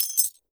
GLASS_Fragment_01_mono.wav